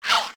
hit_02.ogg